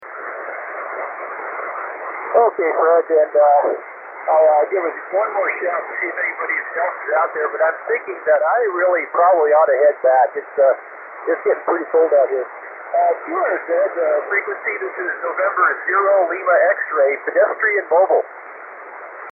Pedestrian Mobile:  Walking and talking.